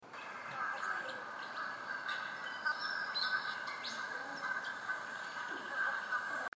Little Pied and Pied Cormorants
Phalacrocorax varius